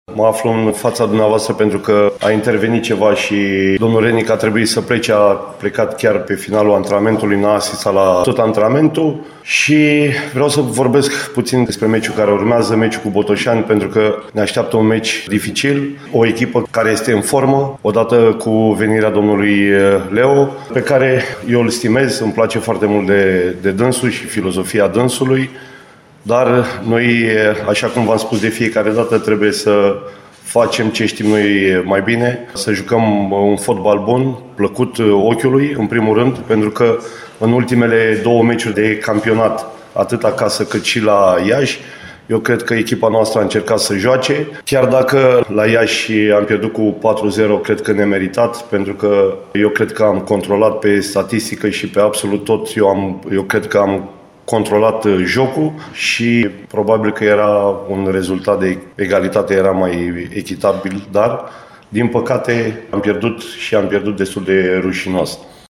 Conferința de presă a prim-divizionarei de fotbal UTA a fost susținută, astăzi